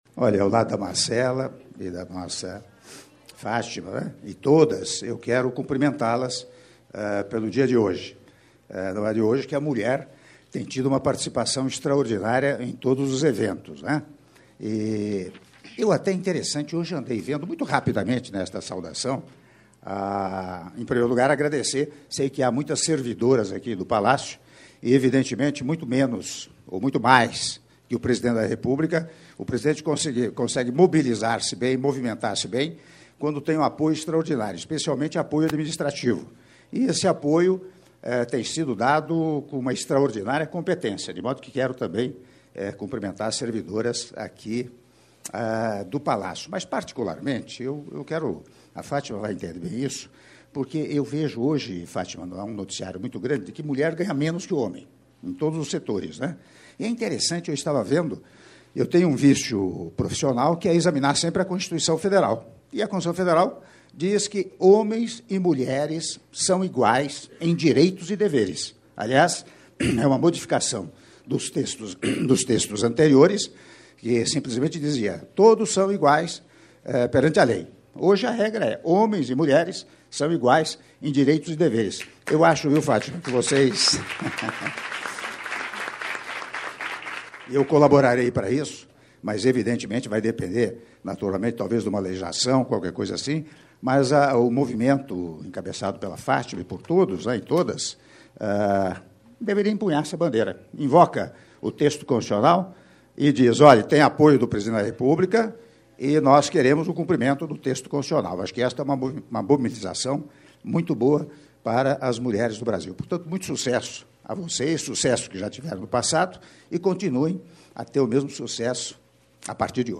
Áudio do discurso do Presidente da República, Michel Temer, durante Evento Comemorativo ao Dia Internacional da Mulher - (02min03s) - Brasília/DF — Biblioteca